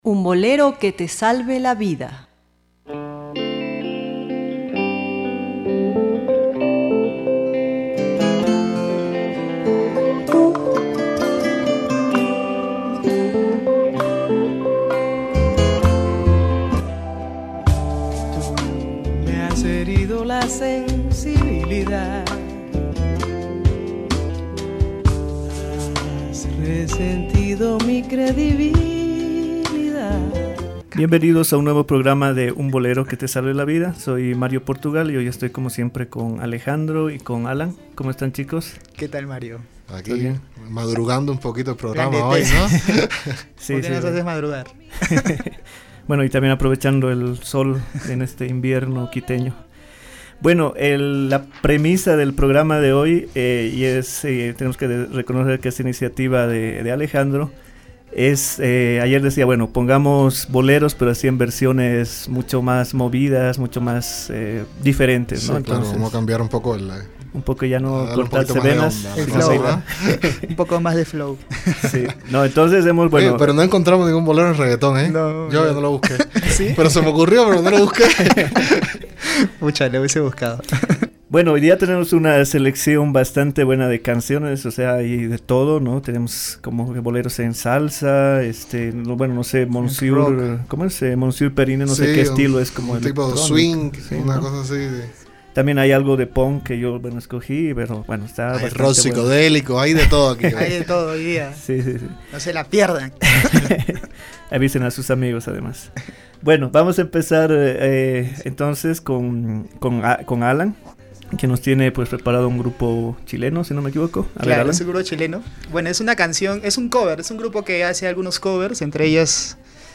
De esta forma, hoy podrás escuchar tus boleros favoritos en ritmos que van desde el punk hasta la salsa.